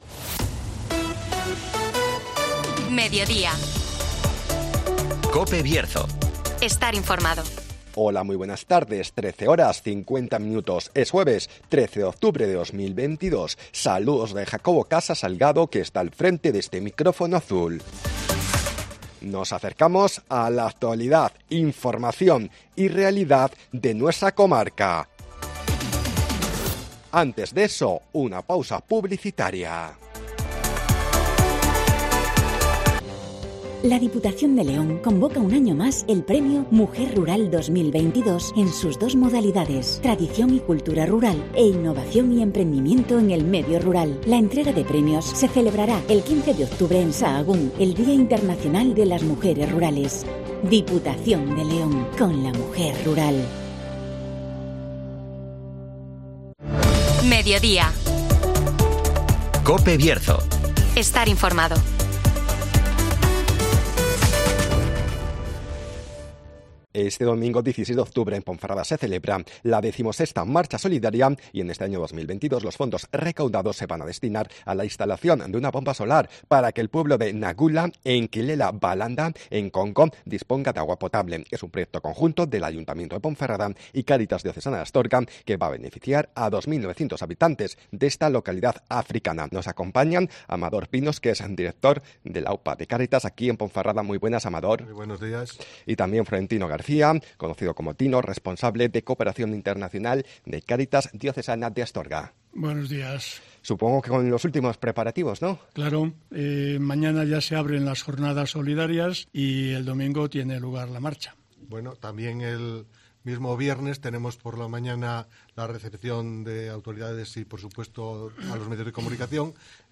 Marcha solidaria en Ponferrada para la instalación de una bomba solar de agua potable en Congo (Entrevista